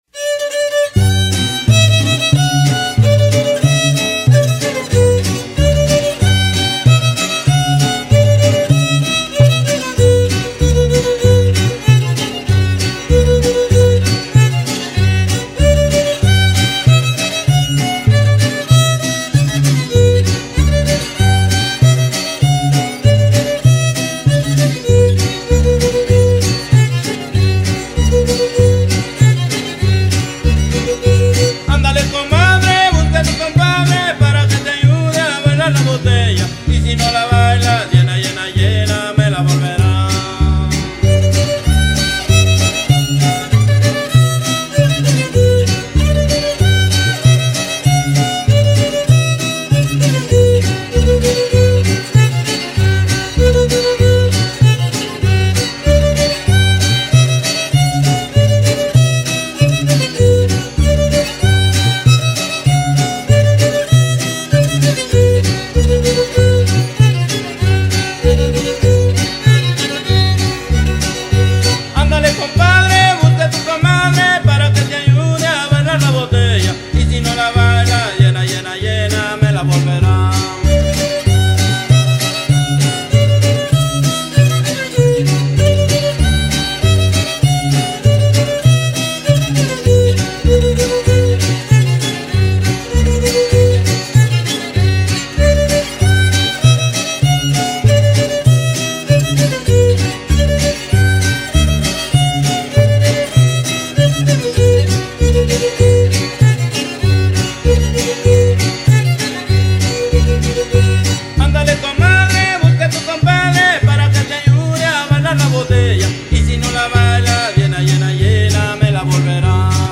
musicólogo.
Locación: NEAR OFFICE.
la botella.mp3 Intro, salida y fondo musical